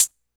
Closed Hats
Hat (47).wav